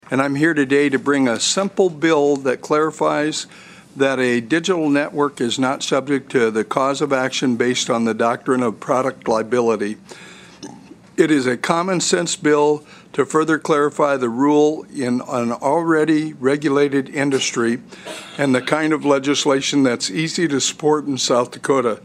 Prime sponsor of the bill was Senator Carl Perry of Aberdeen.